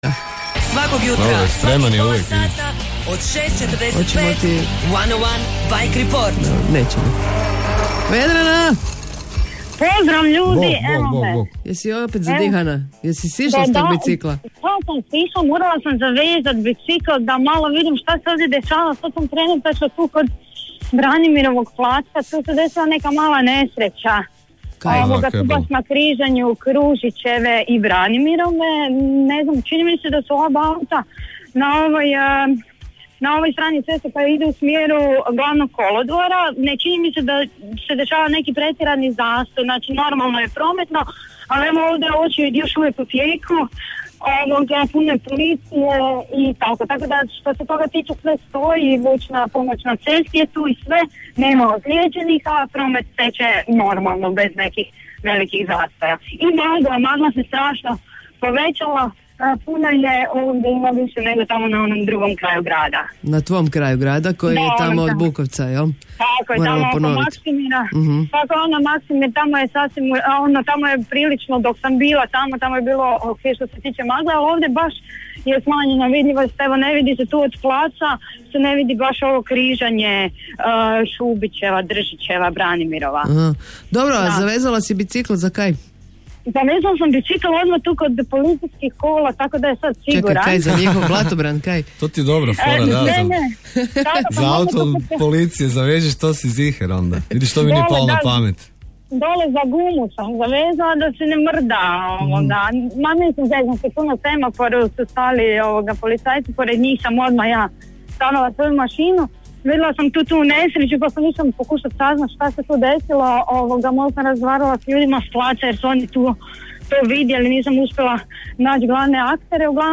Od ponedjeljka do petka, od ranog jutra do Phone Boxa u eteru Radija 101 očekujte redovita javljanja naših dežurnih bi-reportera sa zagrebačkih cesata.